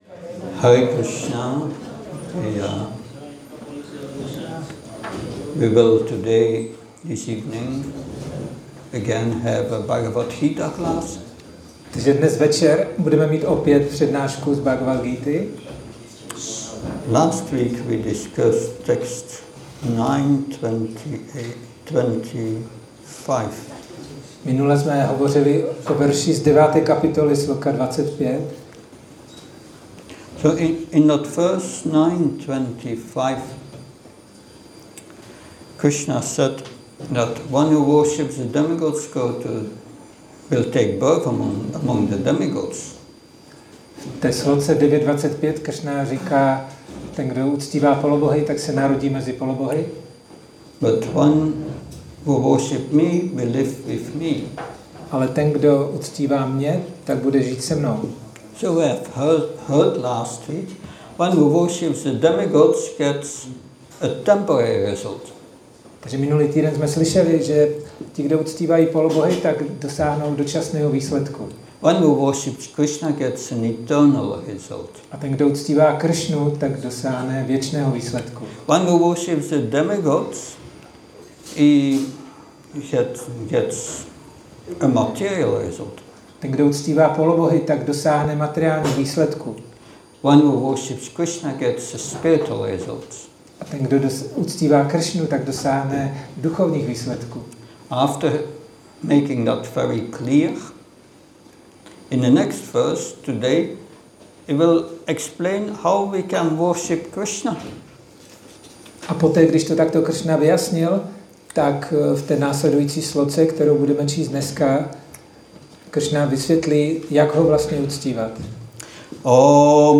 Přednáška BG-9.26 – restaurace Góvinda